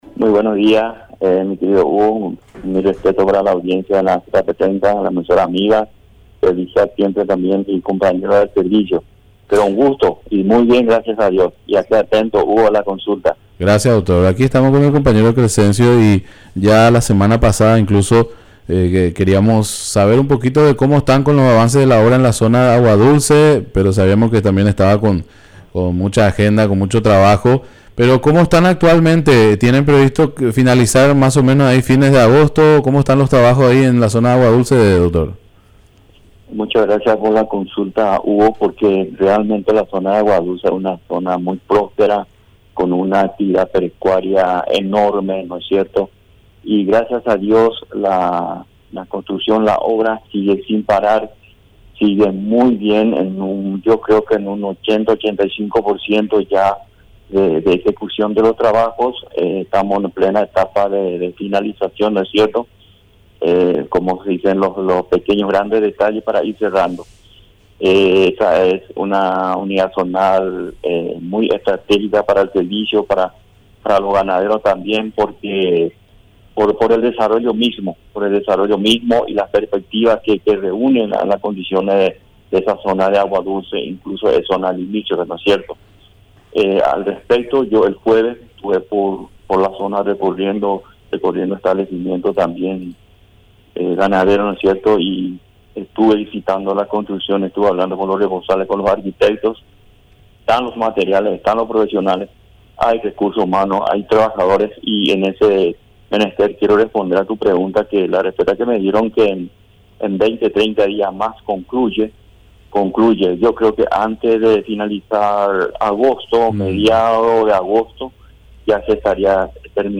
Entrevistas / Matinal 610 Virus, SENACSA en Agua Dulce y app Mymba Tape Jul 29 2024 | 00:17:45 1x 00:00 / 00:17:45 Subscribe Share RSS Feed Share Link Embed